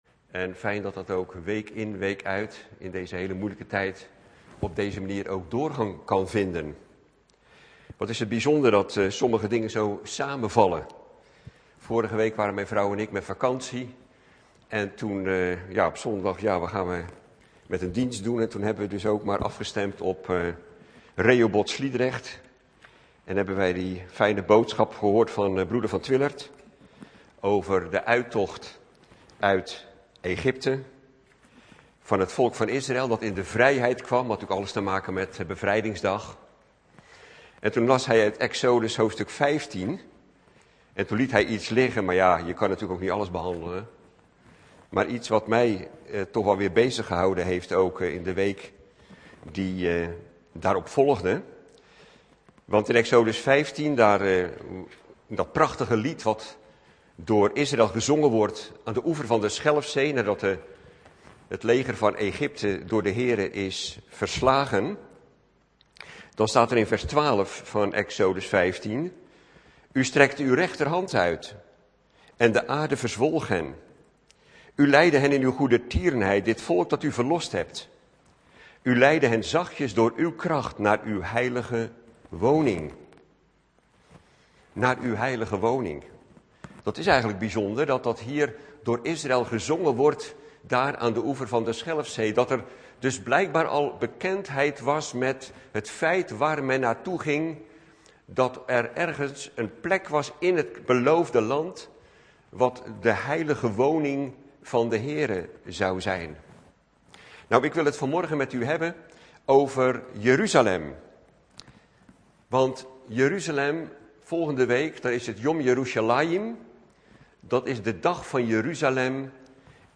In de preek aangehaalde bijbelteksten (Statenvertaling)Exodus 151 Toen zong Mozes en de kinderen Israels den HEERE dit lied, en spraken, zeggende: Ik zal den HEERE zingen; want Hij is hogelijk verheven!